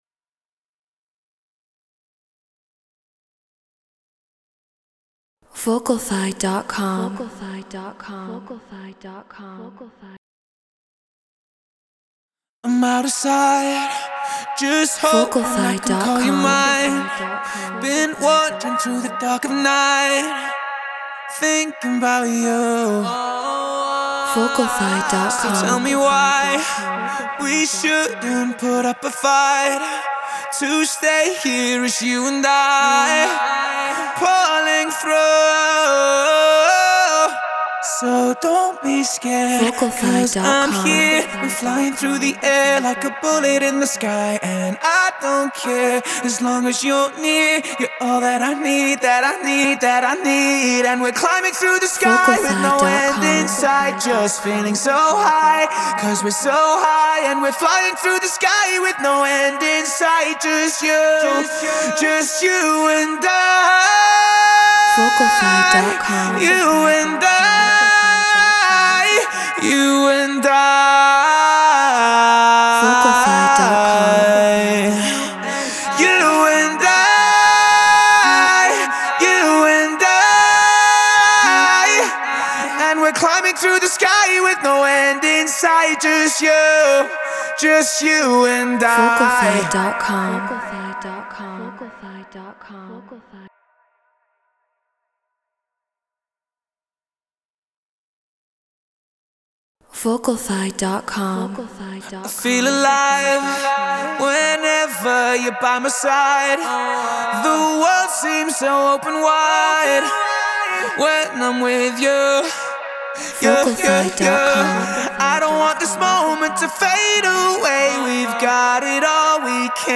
Future Bass 150 BPM Bmaj